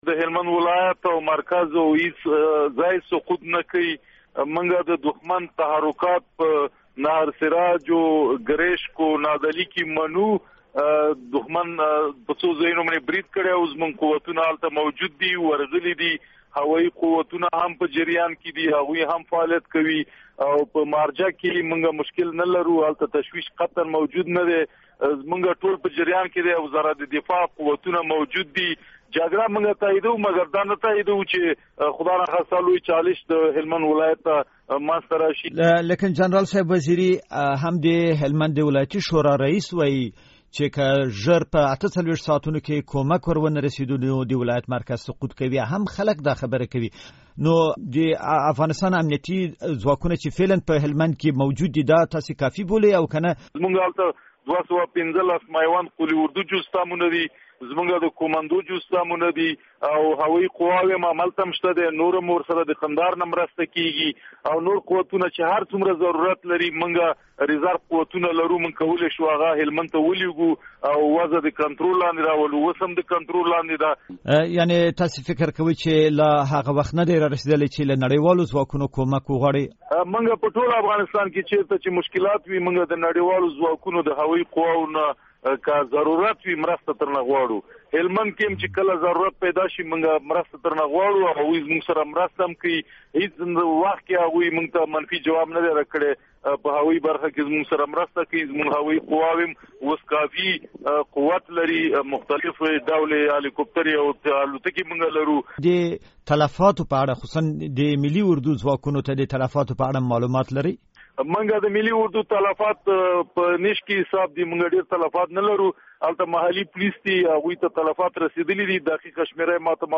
له دولت وزیري سره مرکه